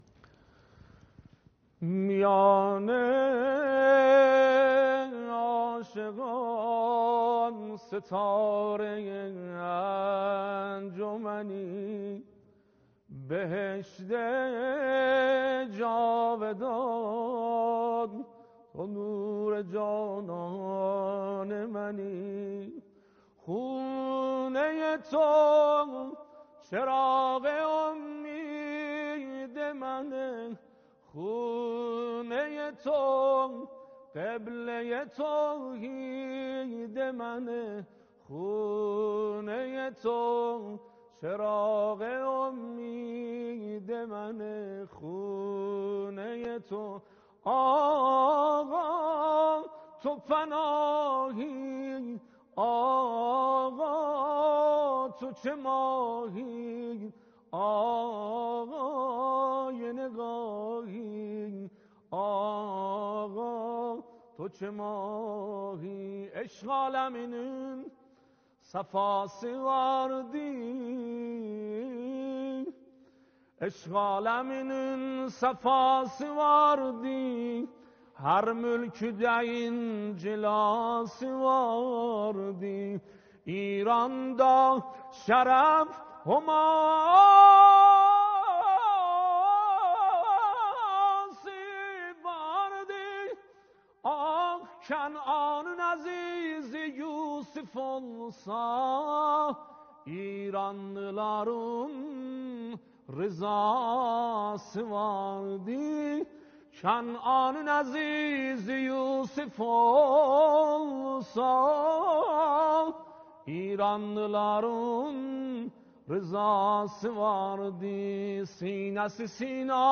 شعرخوانی زیبا- شب ولادت حضرت معصومه (س)-شبکه جام جم -برنامه باهمستان -96